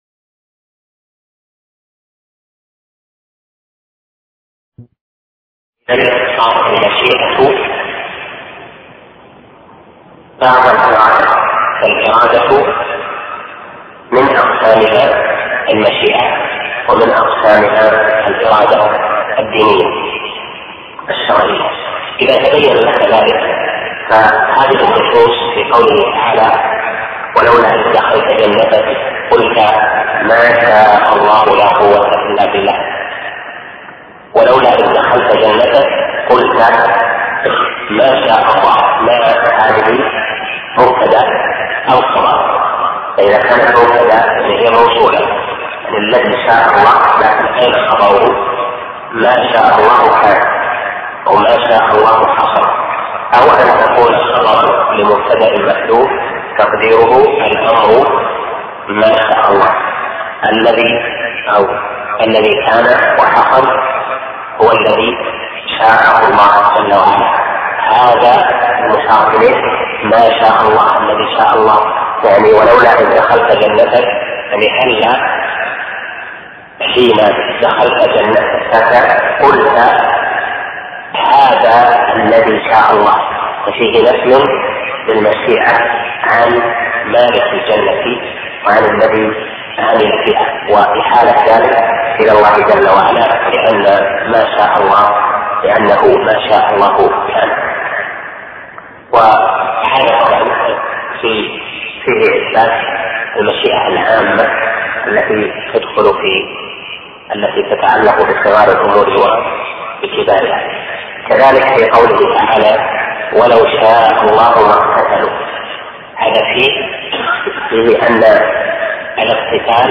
شرح العقيدة الواسطية الدرس السابع